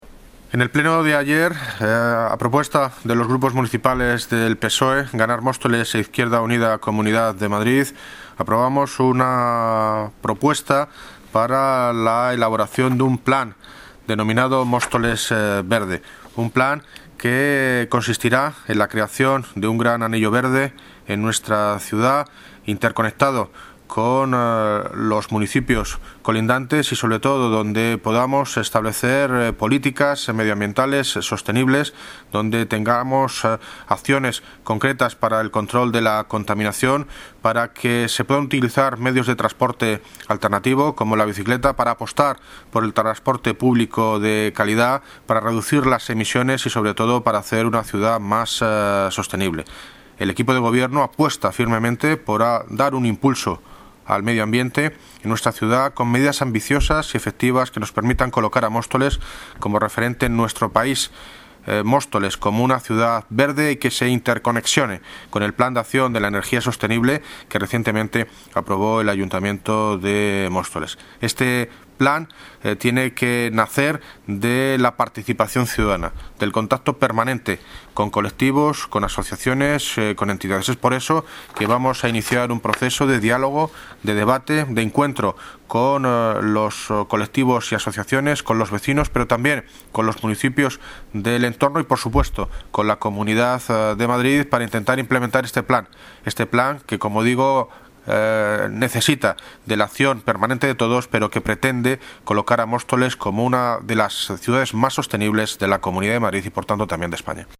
Audio - David Lucas (Alcalde de Móstoles) sobre Plan Móstoles Verde